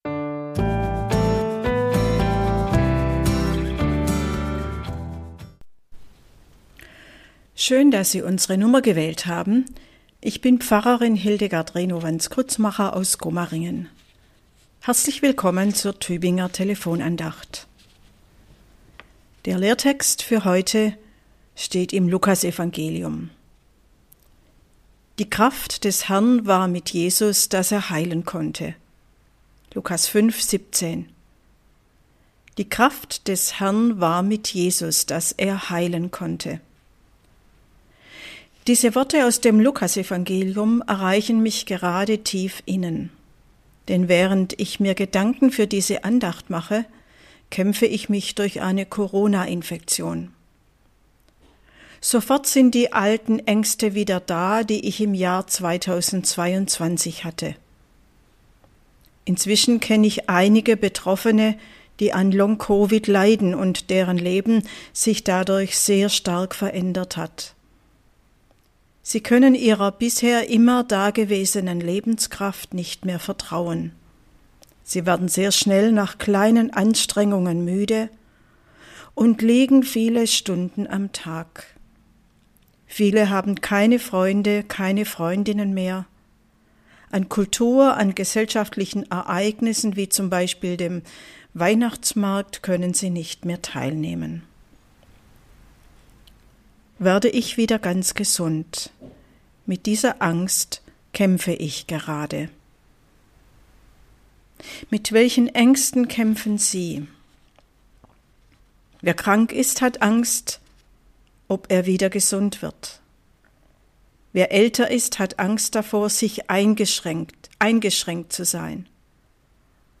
Andacht zur Tageslosung